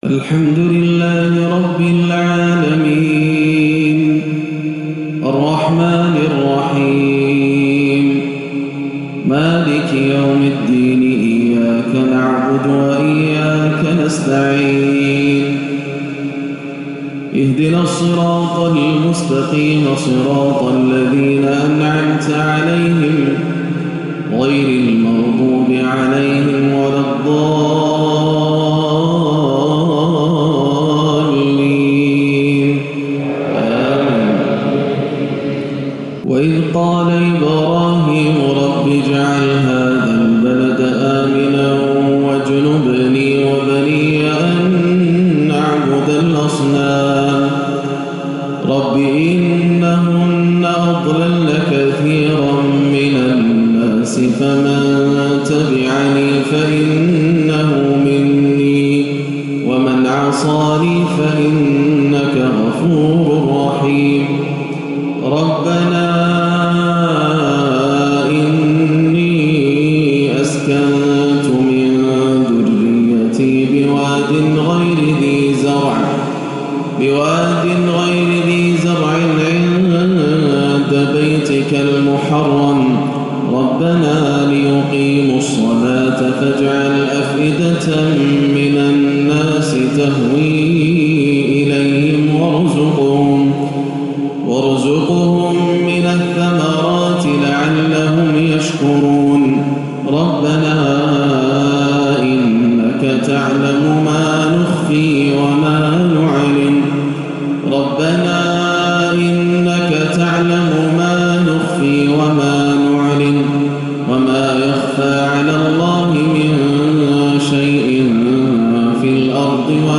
عشاء الثلاثاء 8-4-1439هـ خواتيم سورة إبراهيم 35-52 > عام 1439 > الفروض - تلاوات ياسر الدوسري